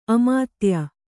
♪ amātya